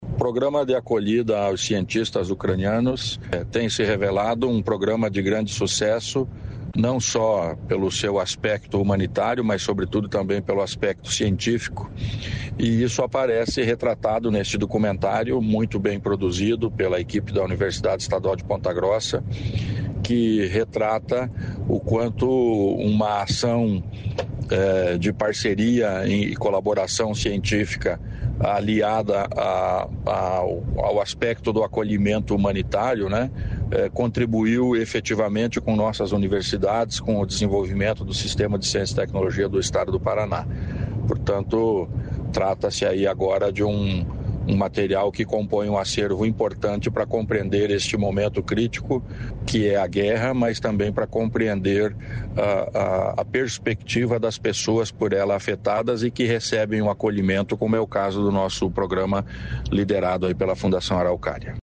Sonora do secretário da Ciência, Tecnologia e Ensino Superior, Aldo Bona, sobre documentário produzido pela UEPG | Governo do Estado do Paraná
ALDO BONA - DOCUMENTÁRIO UCRANIANO.mp3